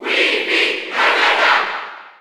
Category:Wii Fit Trainer (SSB4) Category:Crowd cheers (SSB4) You cannot overwrite this file.
Wii_Fit_Trainer_Cheer_Spanish_NTSC_SSB4.ogg